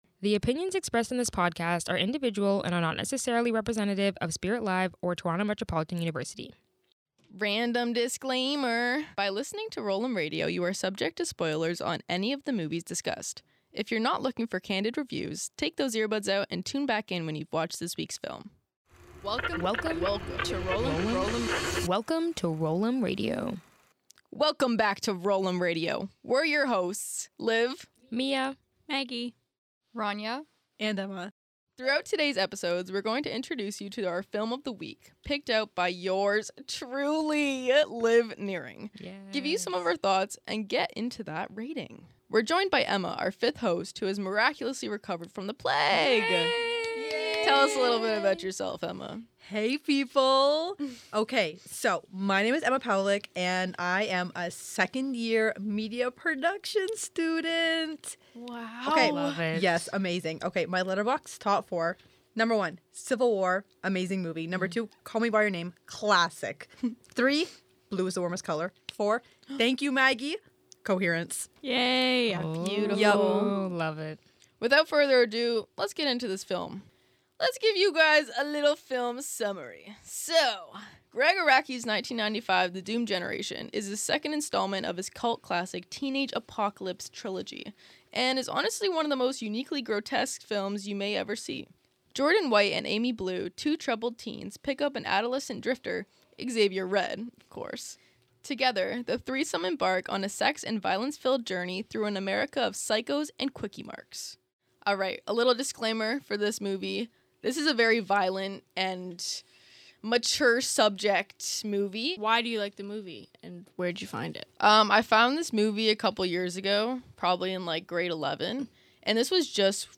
One movie, five critics, and one final verdict. Roll ‘Em Radio is a weekly film podcast where one movie is put through a gauntlet of serious debates, hot takes, and hilarious criteria—all to help YOU decide what to watch next.